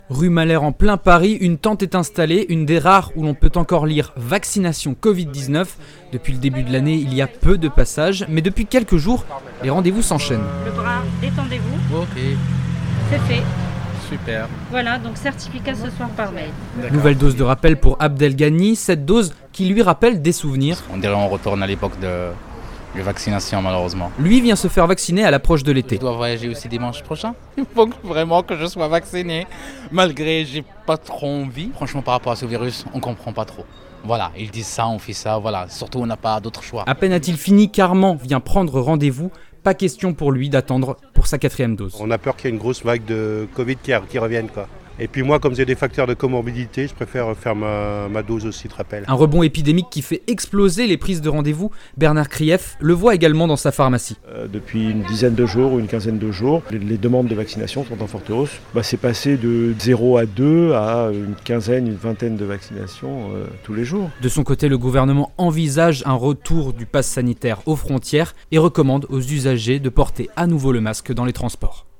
Devant ce centre parisien, une tente est installée.